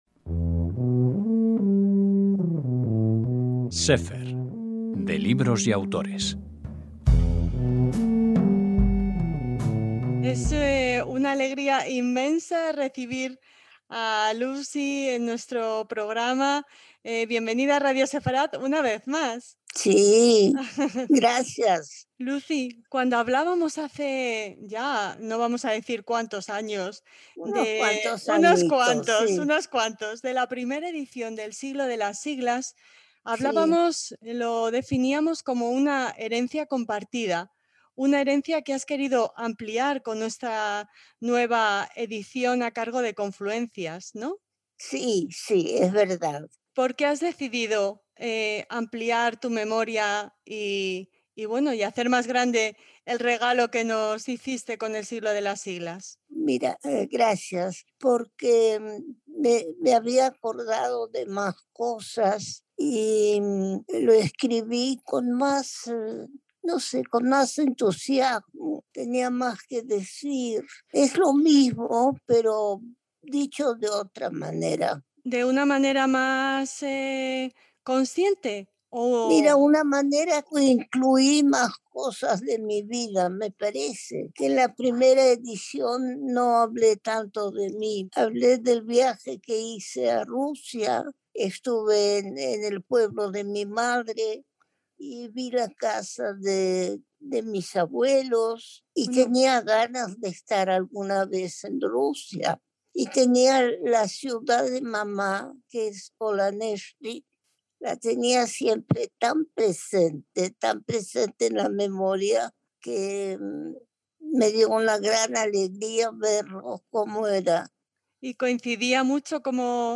HOMENAJE